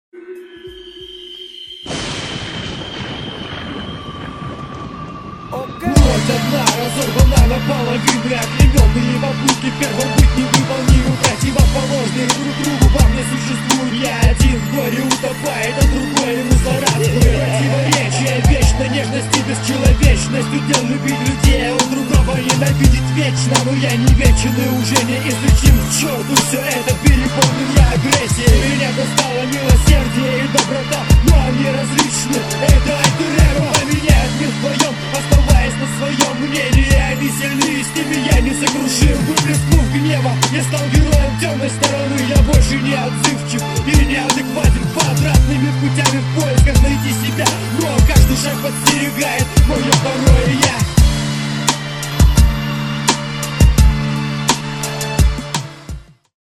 • Жанр: Рэп